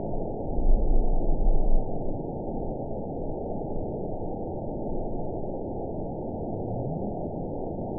event 920149 date 02/29/24 time 23:08:19 GMT (1 month, 3 weeks ago) score 8.14 location TSS-AB10 detected by nrw target species NRW annotations +NRW Spectrogram: Frequency (kHz) vs. Time (s) audio not available .wav